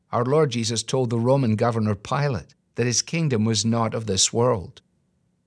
Original voice